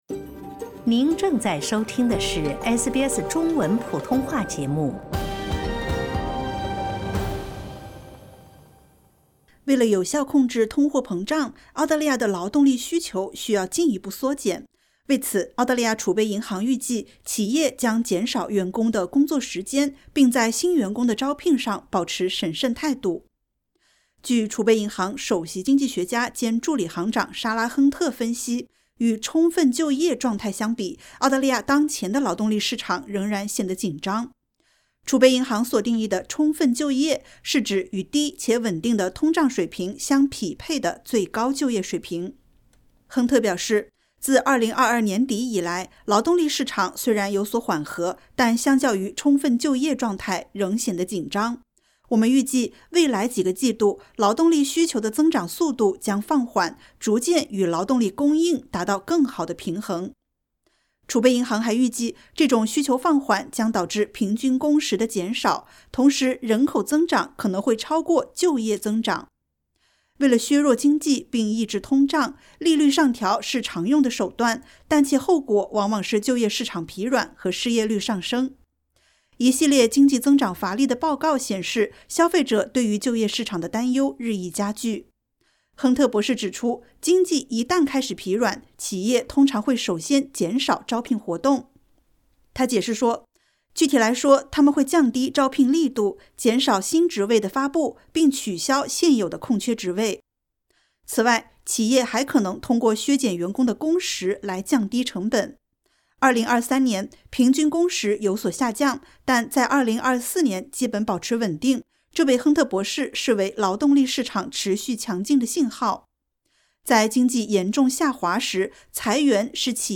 为了有效控制通胀，澳大利亚的劳动力需求需要进一步缩减。为此，澳大利亚储备银行（RBA）预计，企业将减少员工工作时间，并在新员工的招聘上保持审慎态度。点击 ▶ 收听完整报道。